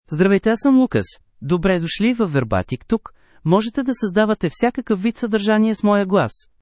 Lucas — Male Bulgarian (Bulgaria) AI Voice | TTS, Voice Cloning & Video | Verbatik AI
Lucas is a male AI voice for Bulgarian (Bulgaria).
Voice sample
Listen to Lucas's male Bulgarian voice.
Lucas delivers clear pronunciation with authentic Bulgaria Bulgarian intonation, making your content sound professionally produced.